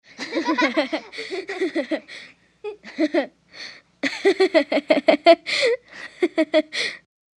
Two Children|Interior | Sneak On The Lot
CROWD - CHILDREN TWO CHILDREN: INT: Laugh, casually.